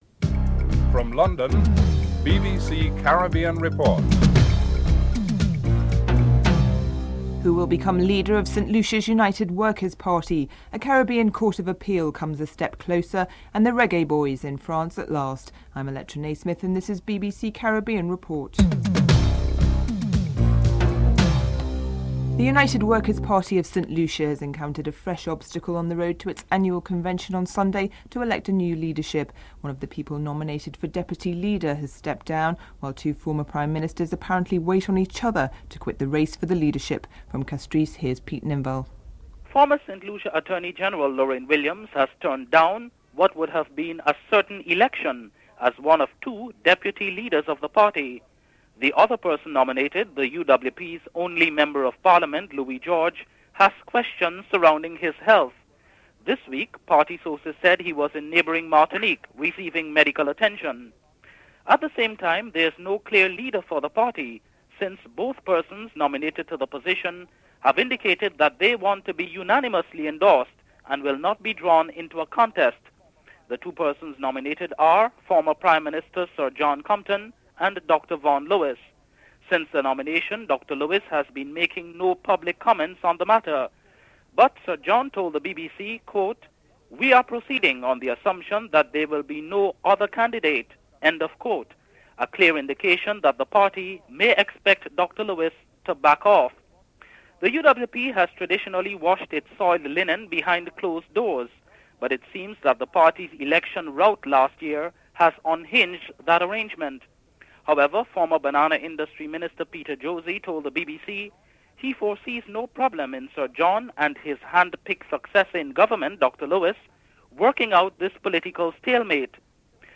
Interview with Jamaica's Attorney General Arnold Nicholson (02:05-03:49)